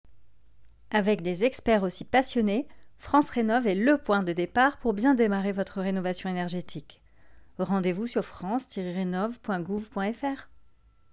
Voix off
16 - 40 ans - Mezzo-soprano